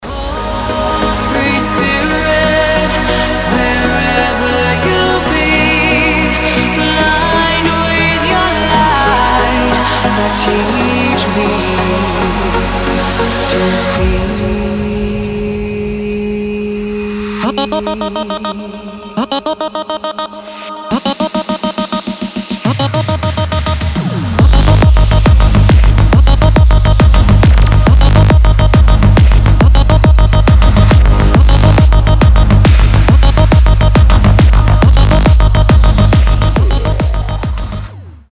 • Качество: 320, Stereo
громкие
женский вокал
Electronic
EDM
красивый женский голос
vocal trance
Стиль: trance, vocal trance